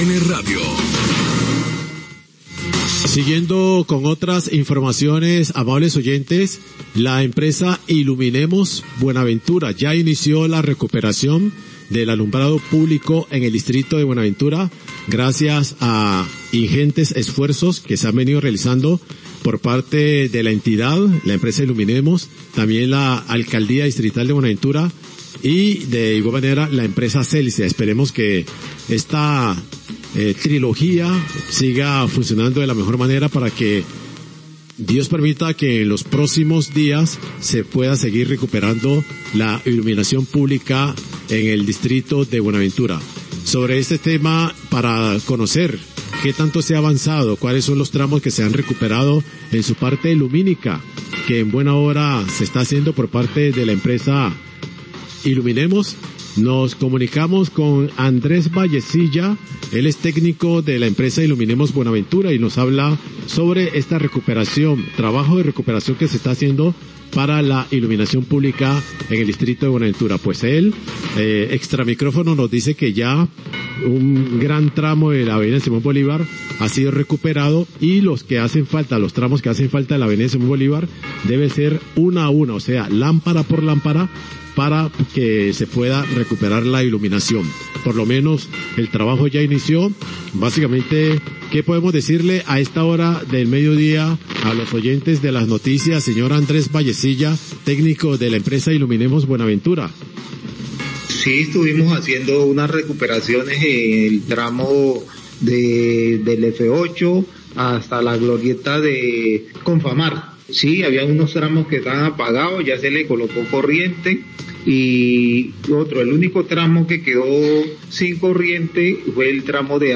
Trabajo de recuperación de alumbrado público, Noticiero Radio Uno, 1228pm